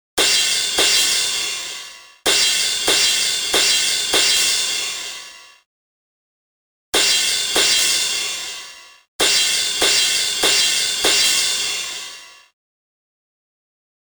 描述：打击乐，打击乐，打击乐909
Tag: 120 bpm Hip Hop Loops Percussion Loops 3.53 MB wav Key : Unknown